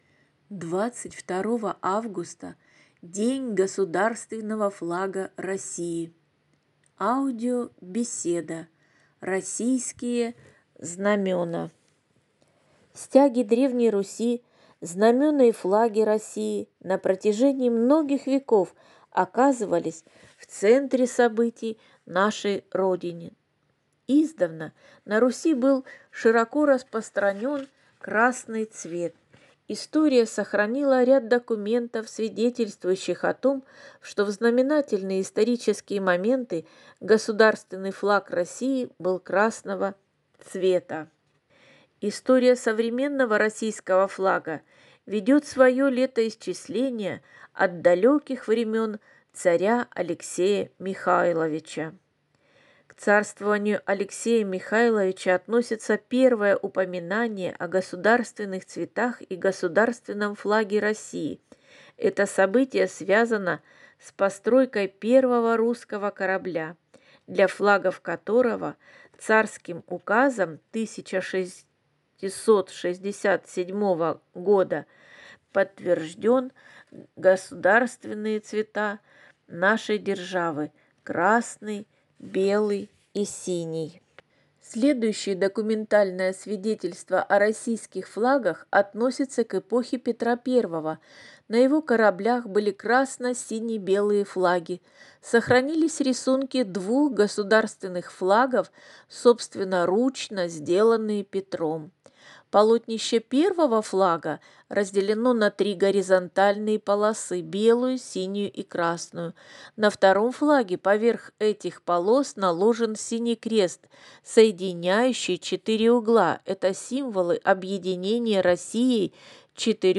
Ко Дню Государственного флага Российской Федерации 22 августа 2025 года в Ростовском пункте выдачи библиотеки для слепых подготовлена аудиобеседа «